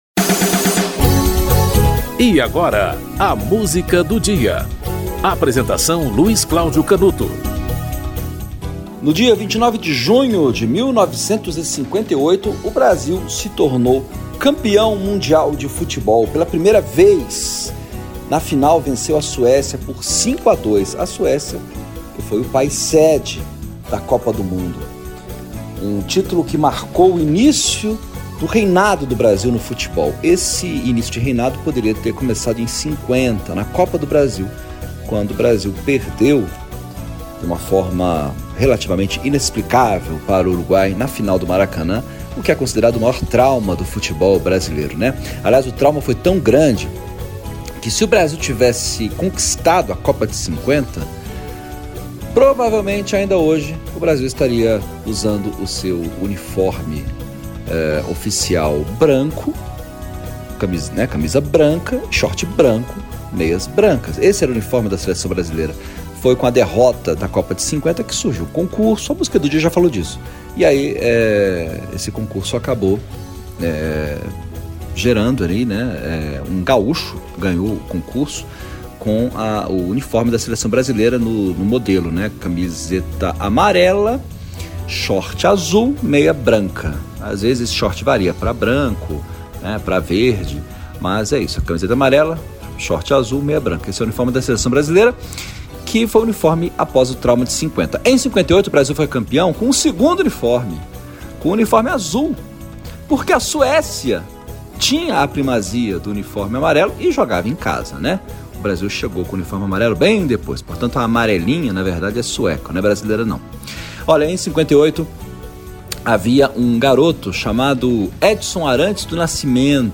Titulares do Ritmo  - A Taça do Mundo é Nossa (Wagner Maugeri, Maugeri Sobrinho, Victor Dagô e Lauro Muller)